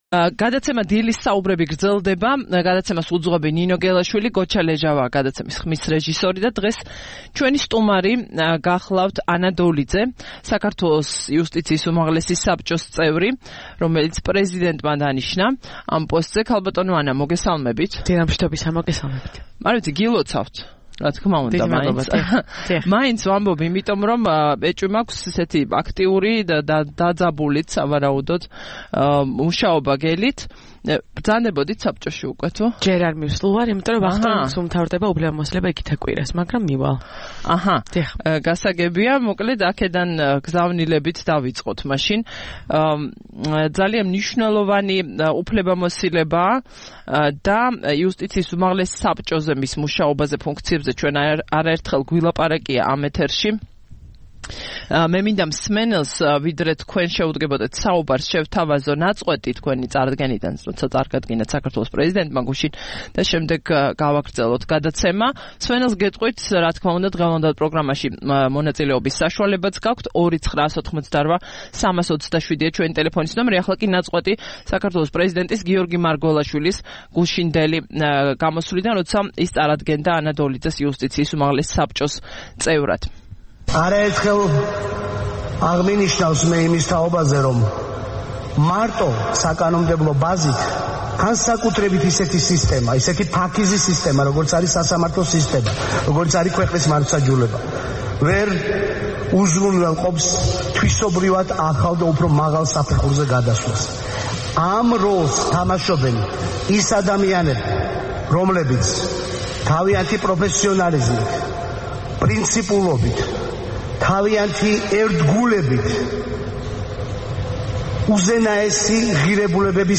სტუმრად ჩვენს ეთერში: ანა დოლიძე
9 იანვარს რადიო თავისუფლების "დილის საუბრების" სტუმარი იყო ანა დოლიძე, იუსტიციის უმაღლესი საბჭოს წევრი.